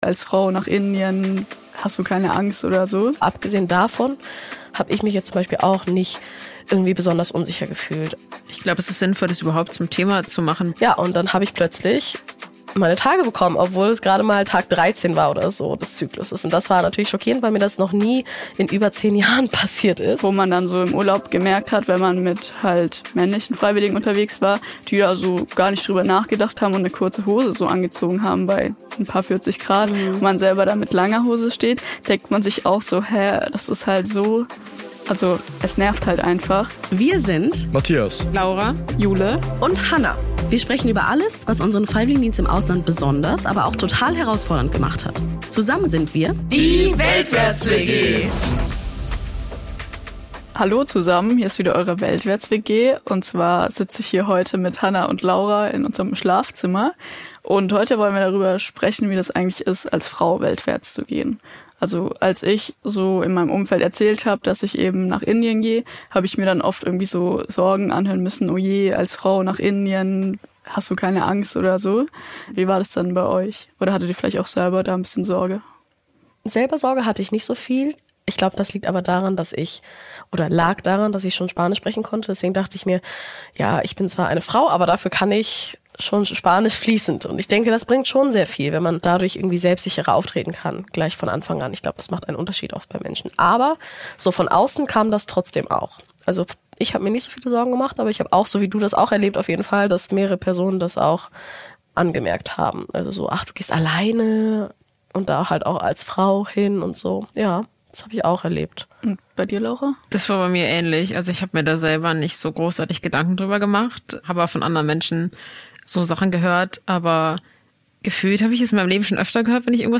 Als Frau weltwärts gehen – mit welchen Herausforderungen und Vorurteilen muss man rechnen? In dieser Episode sprechen die Mitbewohnerinnen der weltwärts-WG über ihre Erfahrungen als Frauen im Ausland: von Fragen zur Sicherheit über kulturelle Rollenbilder bis hin zu körperlichen Veränderungen.